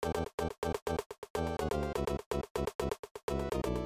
sezione "unused" e non si sente mai nel gioco.